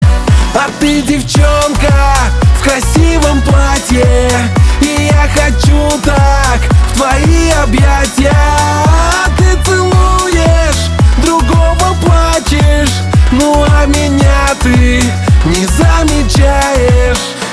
Веселые Дуэт Быстрые Женский голос